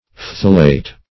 phthalate - definition of phthalate - synonyms, pronunciation, spelling from Free Dictionary Search Result for " phthalate" : The Collaborative International Dictionary of English v.0.48: Phthalate \Phthal"ate\, n. (Chem.)